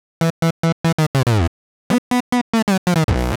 Index of /musicradar/uk-garage-samples/142bpm Lines n Loops/Synths